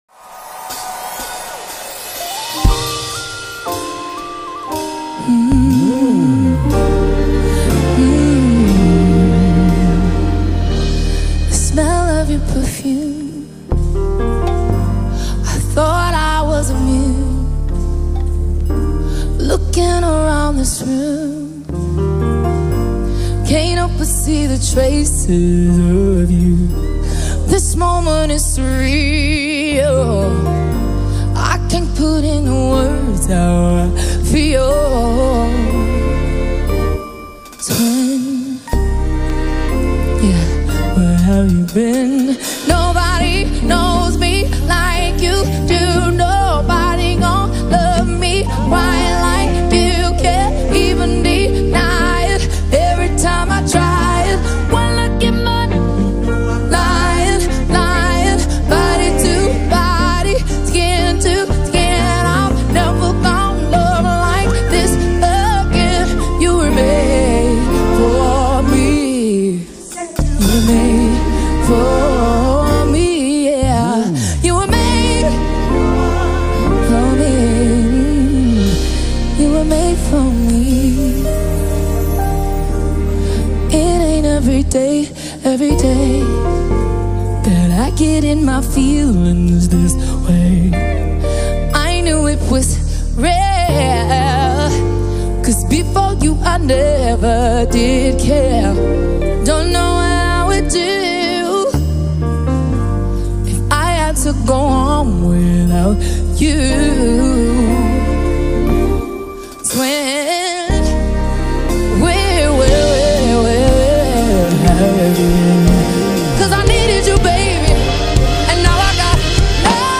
soulful R&B track
showcases her exceptional vocal prowess and emotive delivery